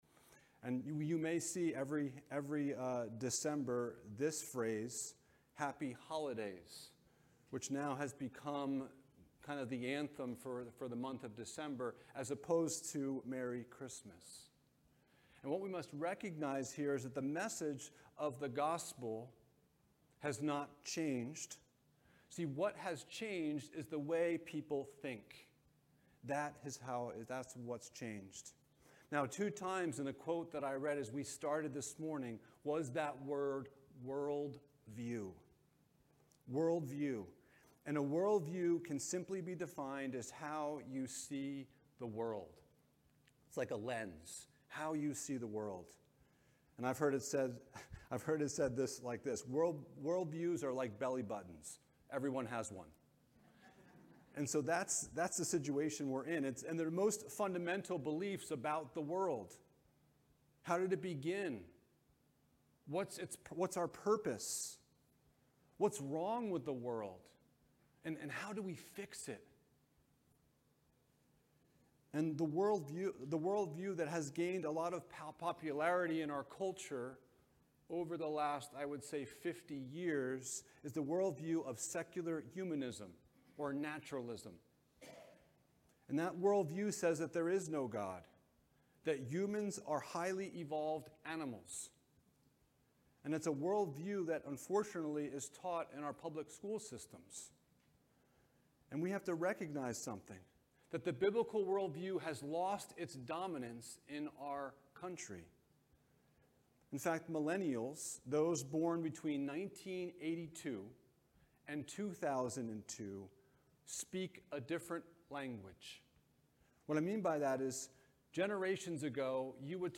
Renovation of the Heart Passage: Nehemiah 9: 9-38 Service Type: Sunday Morning « Rules